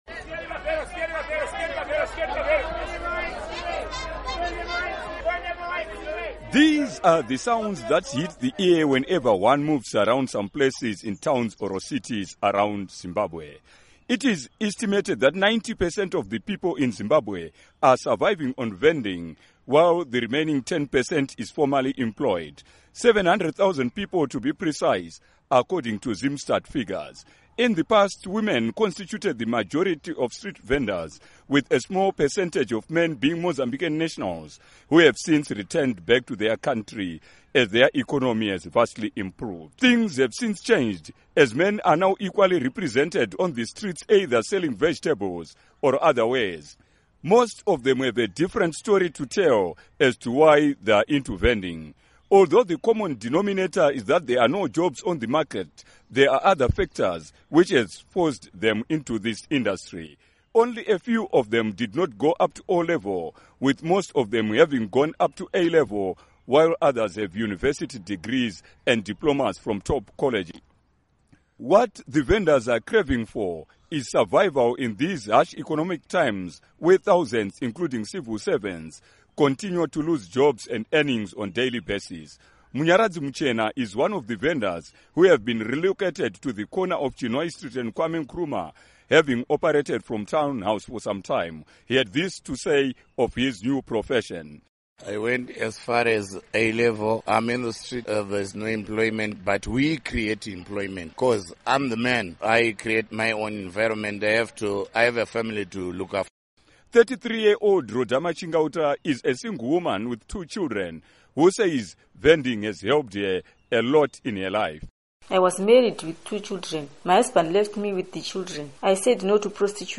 But many of the vendors who spoke with Studio 7 say they hope one day the vendors will contribute to the economy and help their country out of its current financial challenges.
These are the sounds that hit the ear whenever one moves around some places in towns or cities around Zimbabwe.